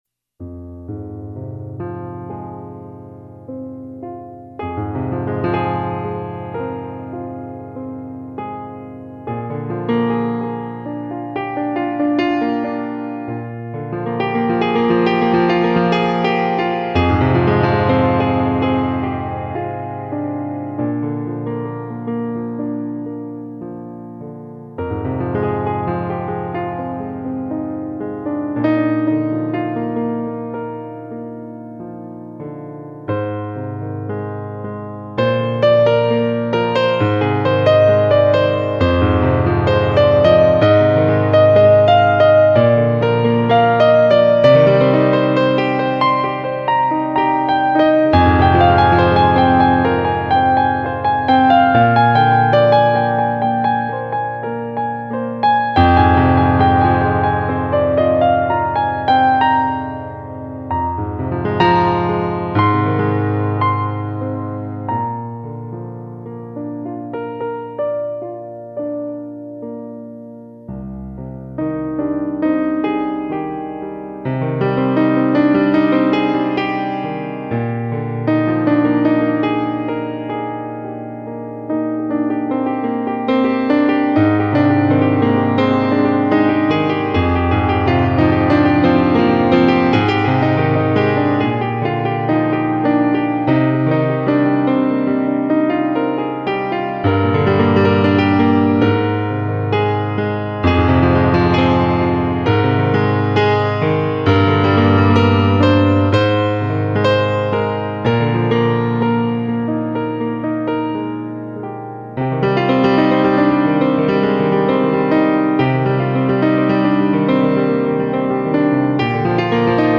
9 beautiful piano solos (c)